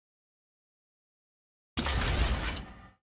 sfx-tier-wings-promotion-from-bronze.ogg